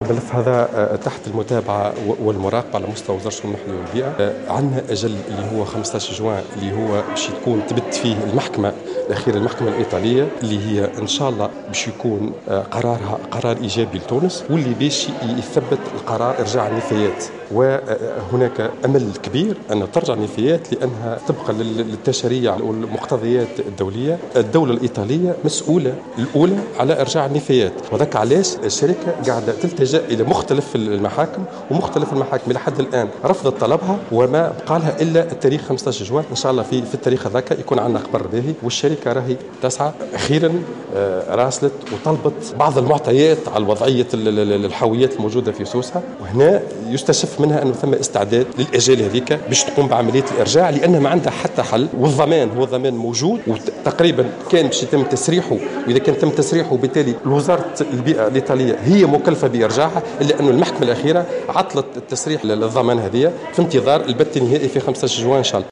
تصريح للجوهرة أف-أم